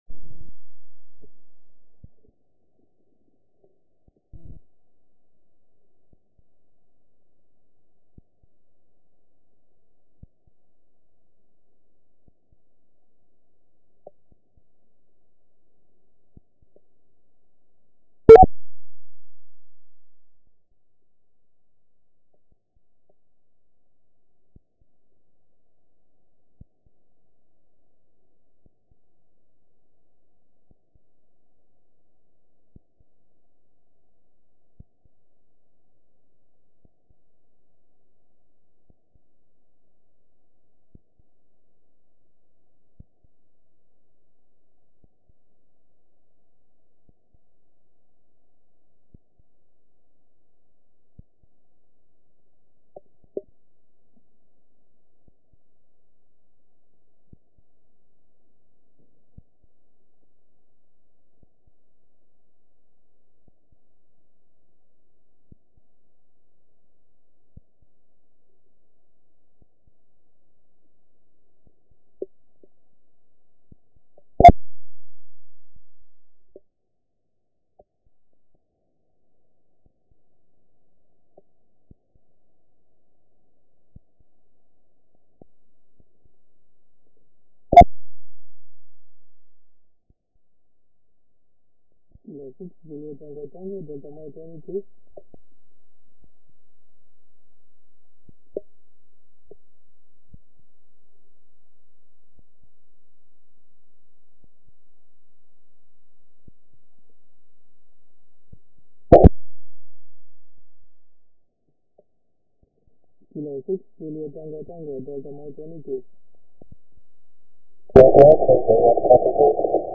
Here are some recordings of the contacts that I have made true satellites.